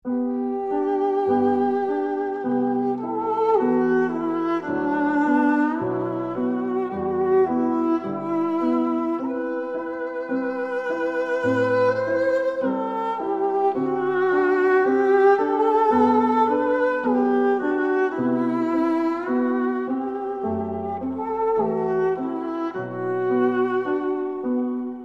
Его голос был немножко ниже, чем у Скрипки, но он был очень тёплым и трепетным.
Альт
альт-20с.mp3